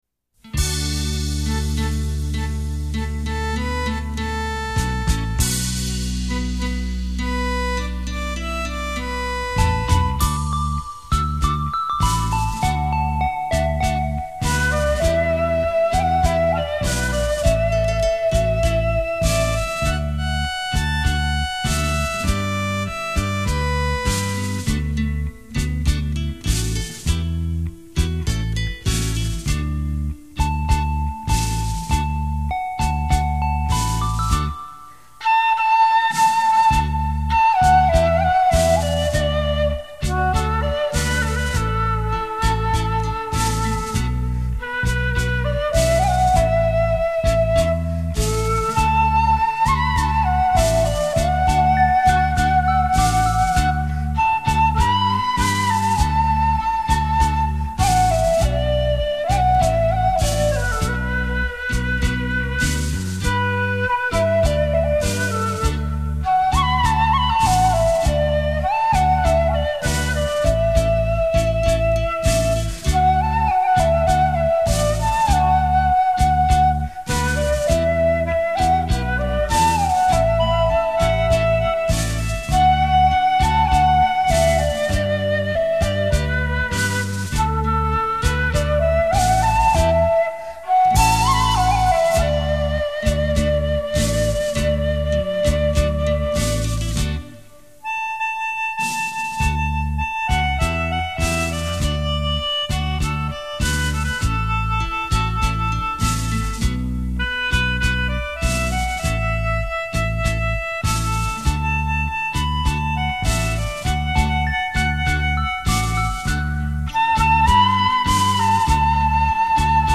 音乐类型: 民乐
樂壇超炫演繹洞簫電子琴作品，旋律優雅，抒
情逸致，曲曲沁心,繞場立體音效 發燒音樂重炫